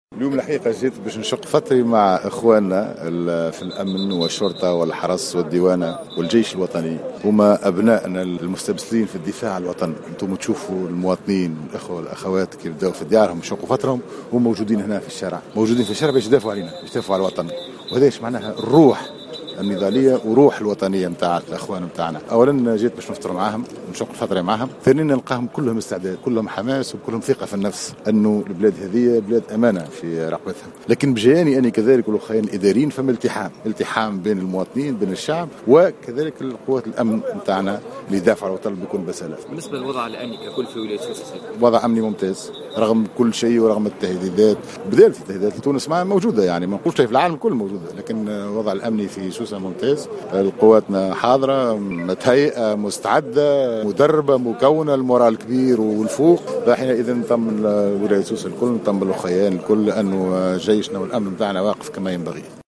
و أكد فتحي بديرة في تصريح للجوهرة "اف ام" أن الوضع الأمني بولاية ممتاز مشددا على الجاهزية التامة لمختلف التشكيلات الأمنية والعسكرية بالجهة لتأمين كافة النقاط الحساسة فيها.